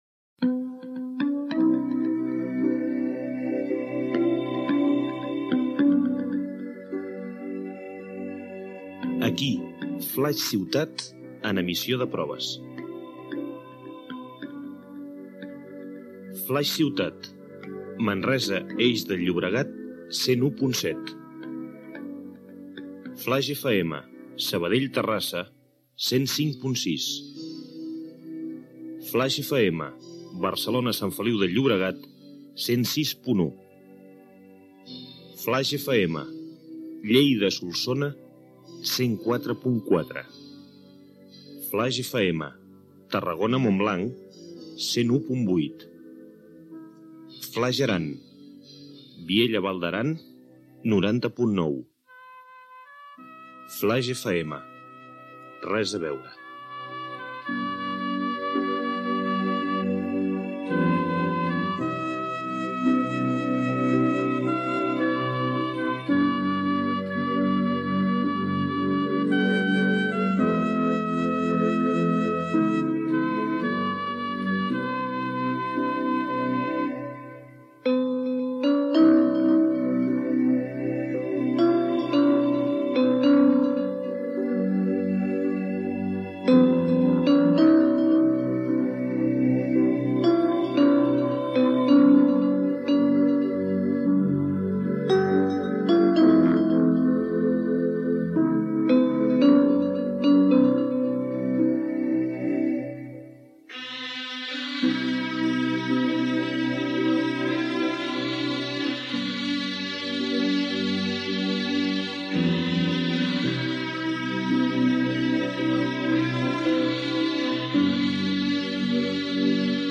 Identificació de l'emissora en proves i localitats i freqüències per on transmet Ràdio Flaix. Melodia basada en el "Cant dels Segadors".
Presentador/a